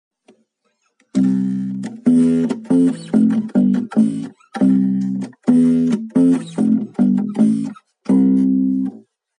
Bassriff-Quiz